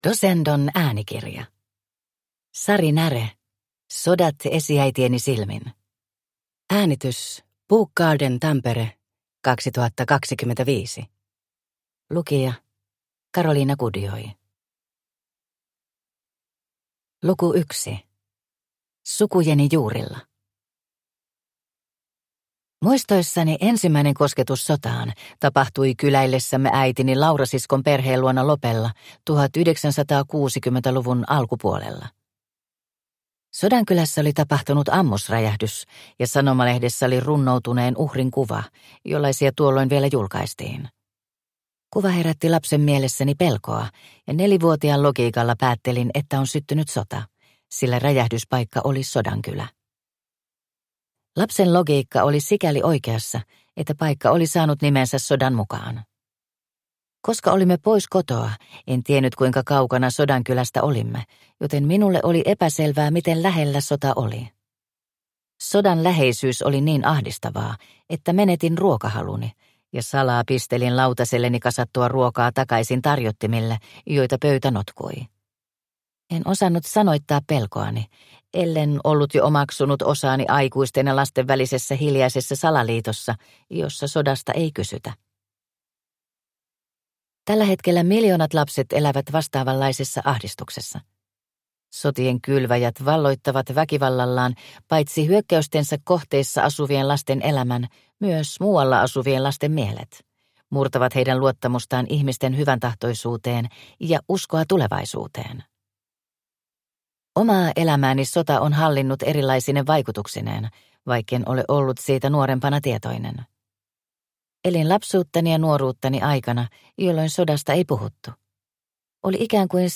Sodat esiäitieni silmin – Ljudbok